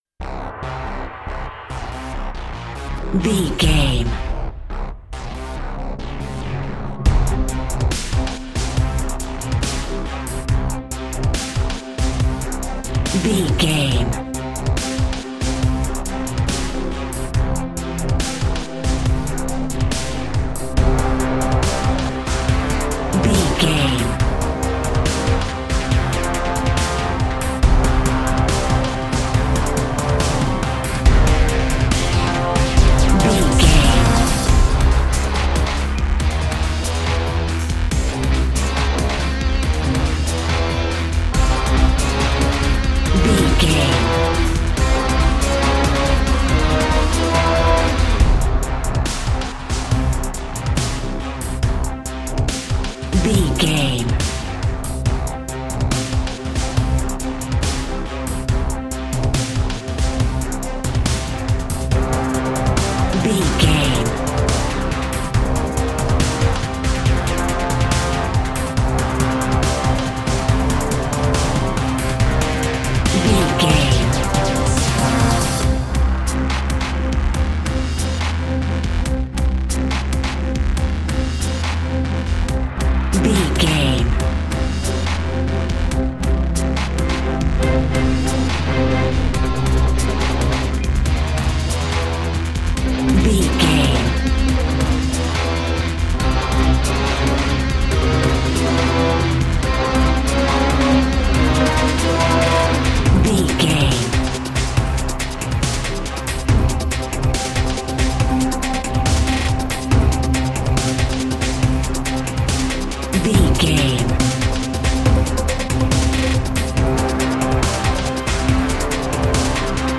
Aeolian/Minor
strings
drums
drum machine
synthesiser
brass
orchestral
orchestral hybrid
dubstep
aggressive
energetic
intense
bass
synth effects
wobbles
heroic
driving drum beat
epic